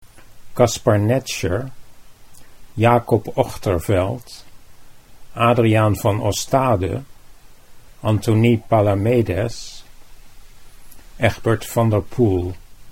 How to Pronounce the Names of Some Dutch Painting Masters